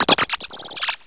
pokeemerald / sound / direct_sound_samples / cries / noibat.aif